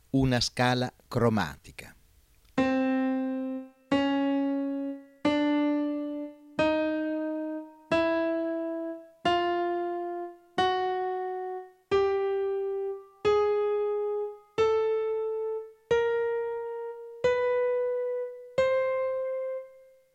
In questo caso, praticamente, si suonano, in successione uno dopo l’altro, i tasti bianchi e i tasti neri.
09. Ascolto della scala cromatica.
09_Scala_cromatica.wma